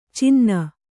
♪ cinna